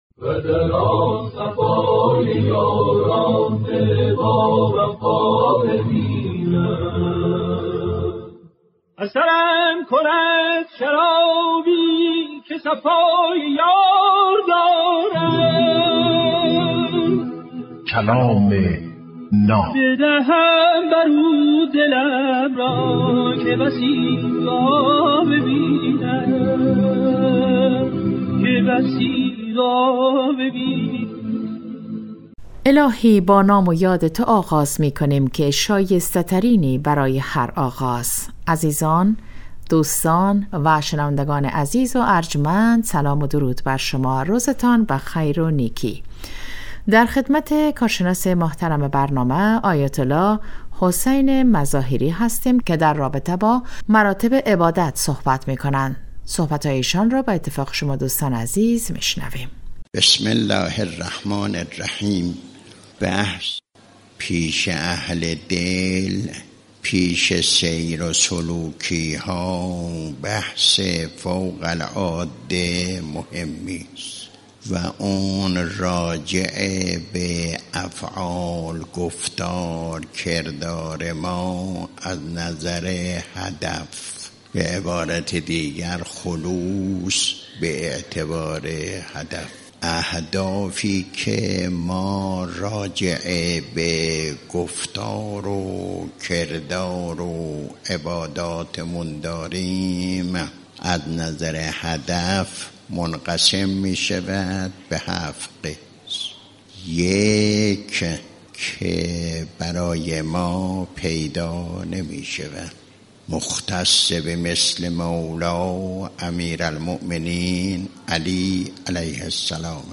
کلام ناب برنامه ای از سخنان بزرگان است که هر روز ساعت 7:35 عصر به وقت افغانستان به مدت 10دقیقه پخش می شود.